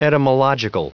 Prononciation du mot etymological en anglais (fichier audio)
etymological.wav